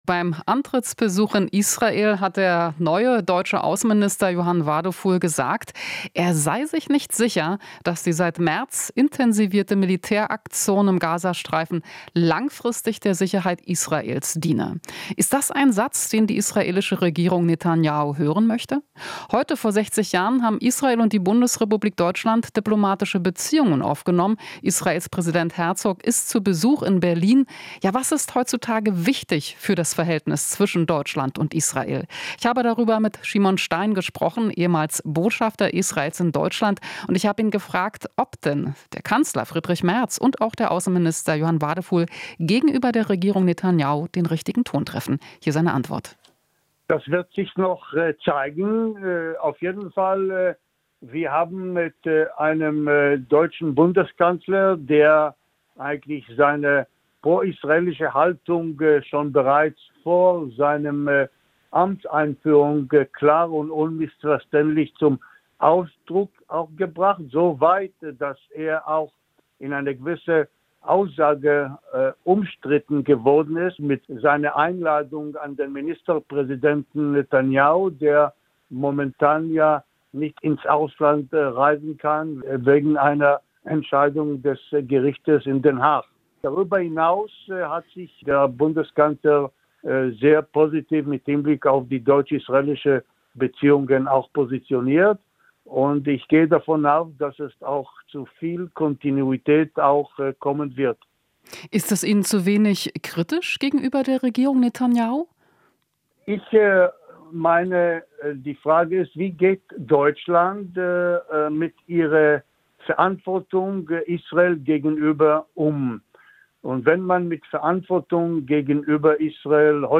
Interview - Ex-Botschafter Stein: Sorge um Israel ist Teil der Verantwortung